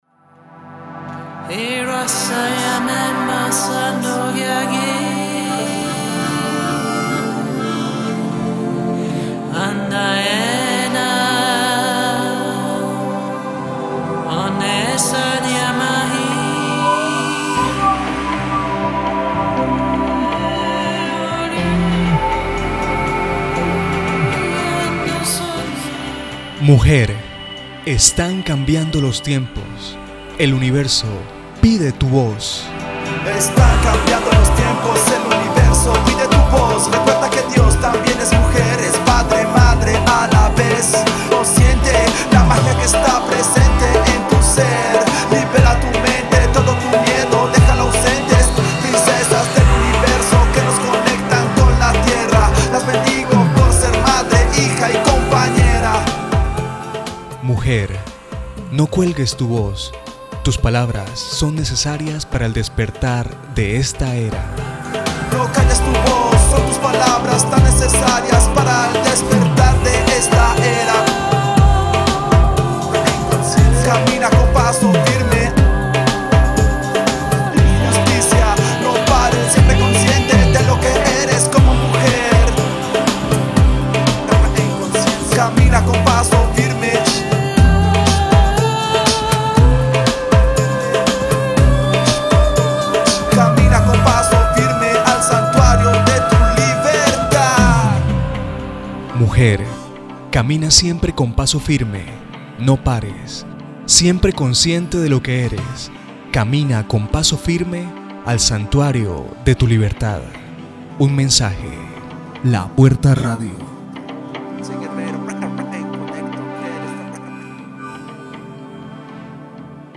kolumbianisch
Sprechprobe: Sonstiges (Muttersprache):